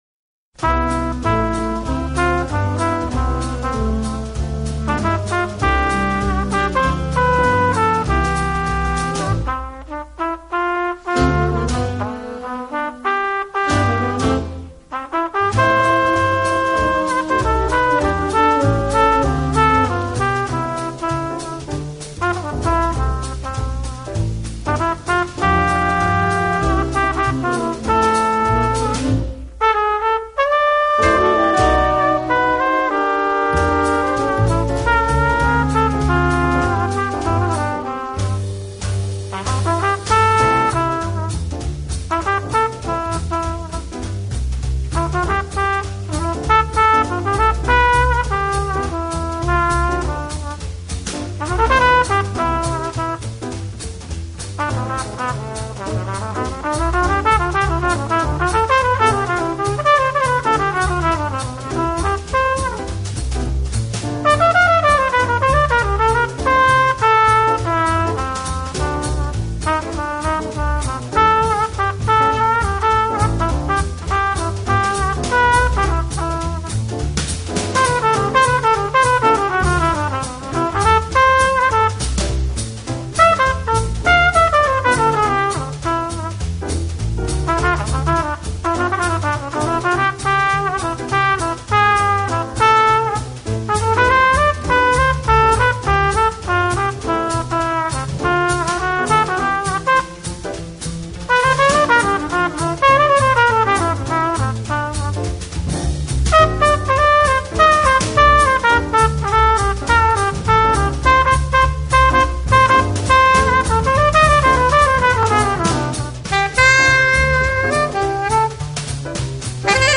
【爵士小号】
专辑类型：Jazz
纳尼等的合作演出，流畅无间的音乐线条中蕴含醇厚温润音色，铺陈之曼妙旋律